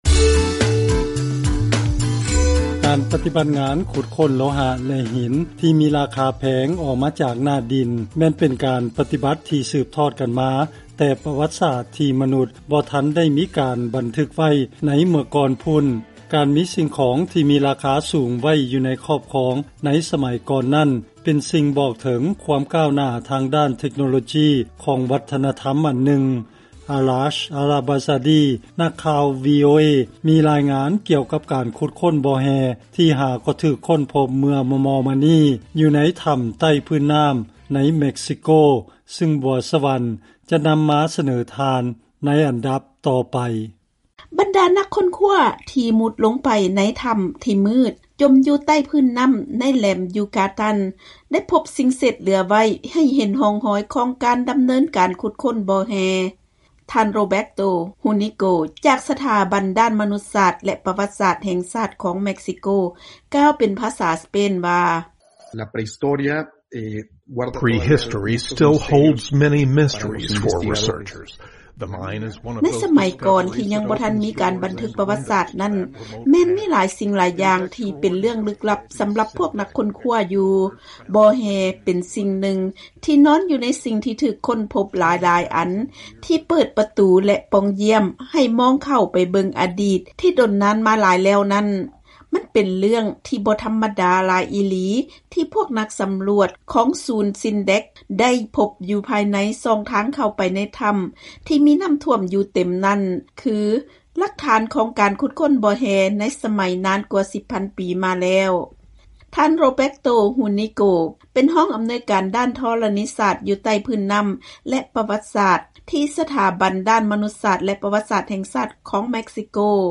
ເຊີນຟັງລາຍງານການຄົ້ນພົບການຂຸດຄົ້ນບໍ່ແຮ່ຂອງສະໄໝບູຮານໃນເມັກຊິໂກ